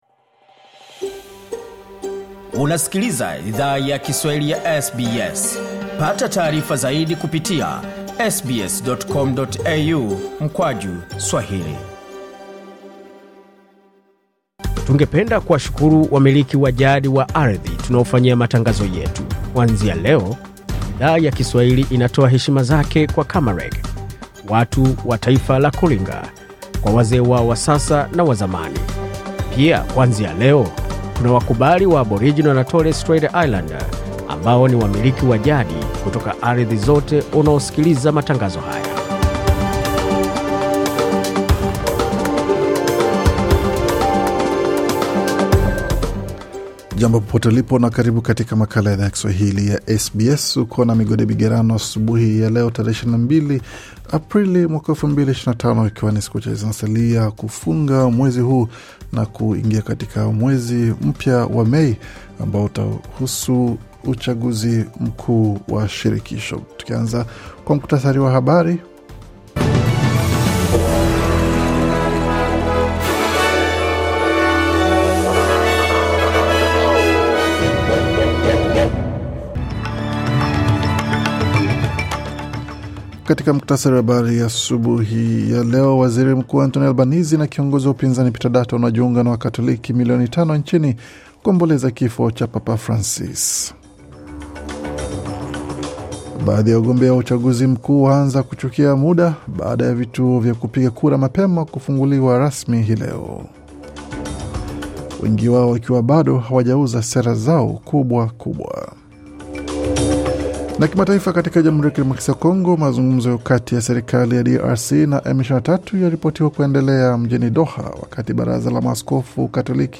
Taarifa ya Habari 22 Aprili 2025